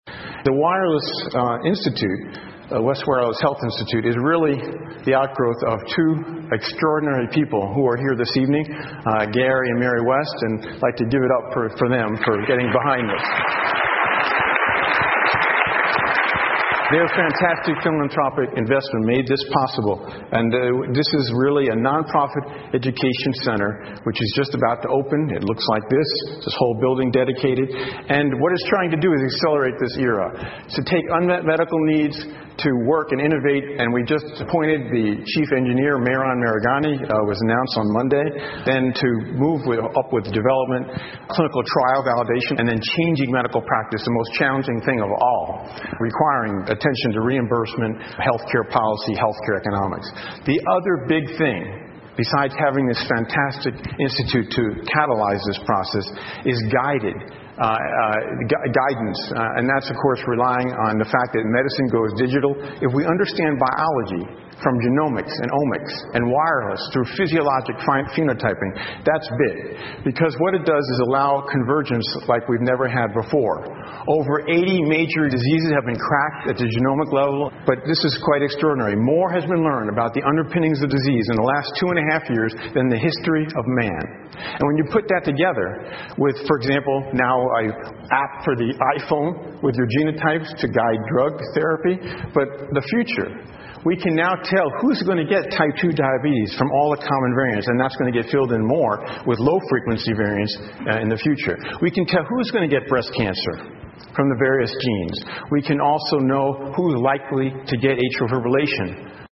TED演讲:未来医疗的无线化() 听力文件下载—在线英语听力室